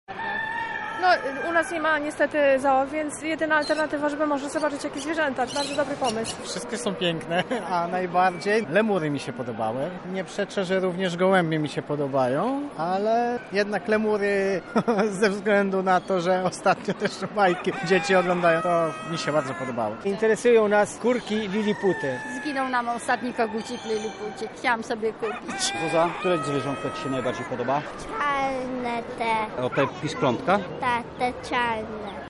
ZooPark w Lublinie
Sprawdzaliśmy, co najbardziej przykuło ich uwagę.